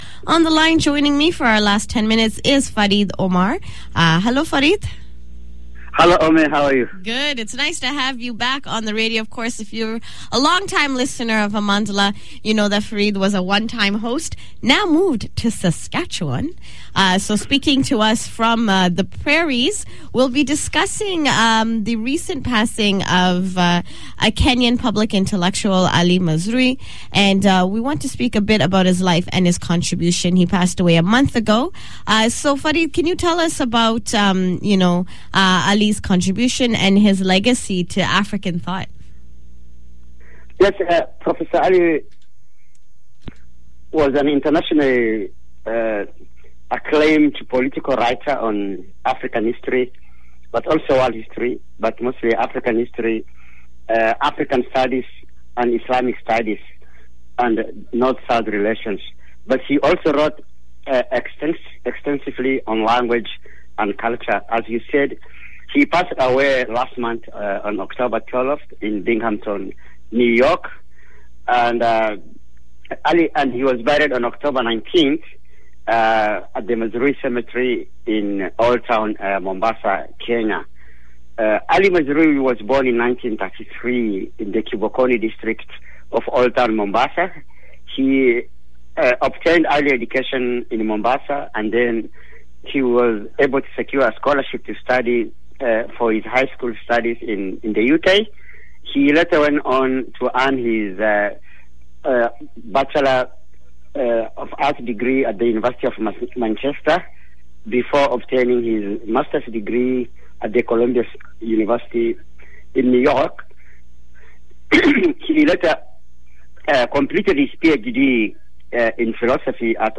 Interview
Recording Location: CHRY Toronto
Type: Weekly Program